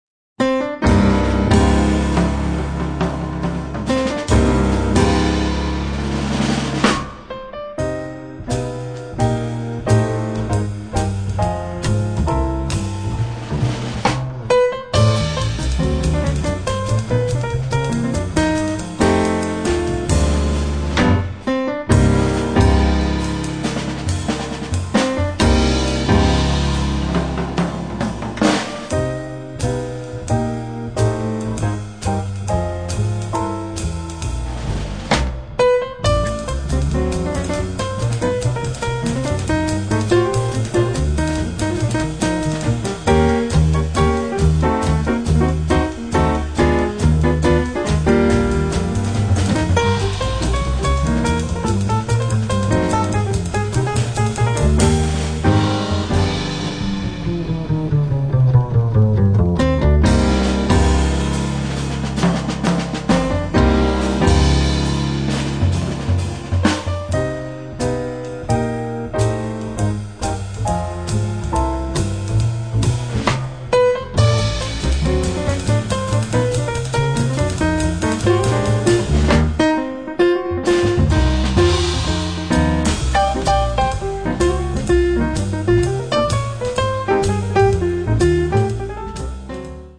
piano
batteria
contrabbasso